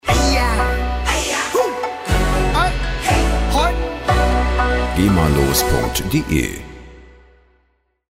Show Opener
Musikstil: Asia Music
Tempo: 120 bpm